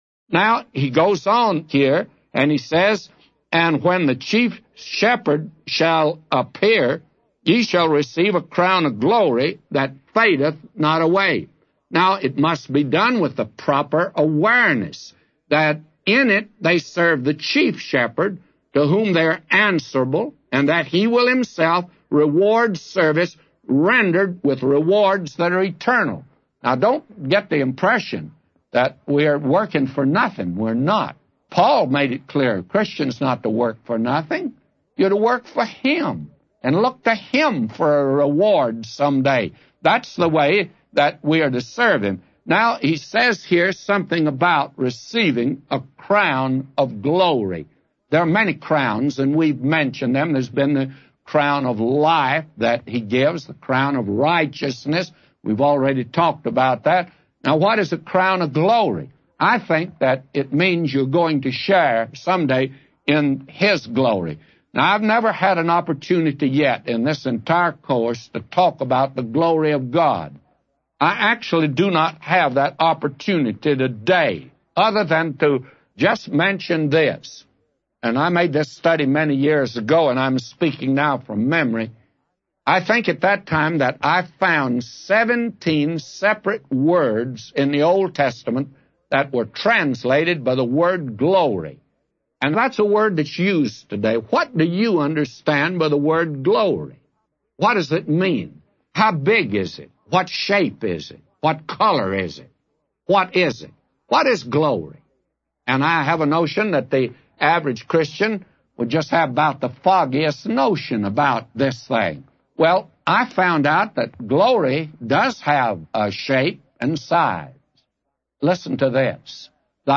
A Commentary By J Vernon MCgee For 1 Peter 5:4-999